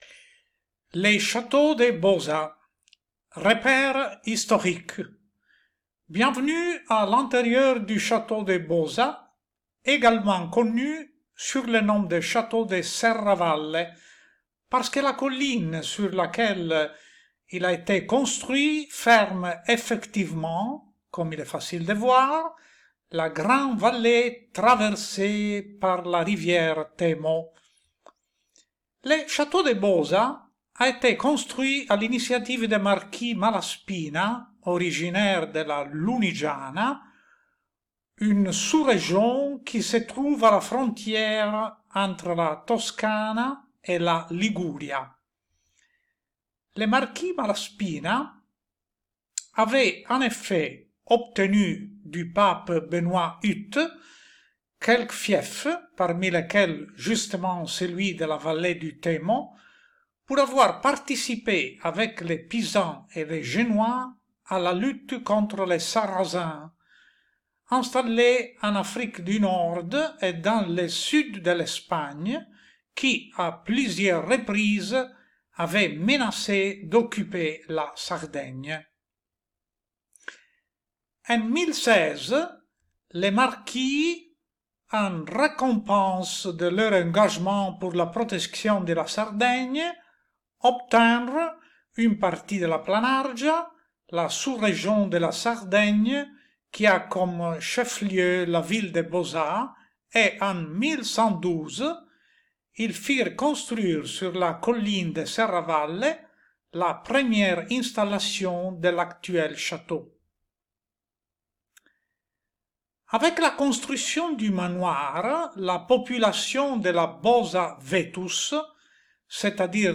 Audioguide - Audioguides